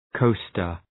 Προφορά
{‘kəʋstər}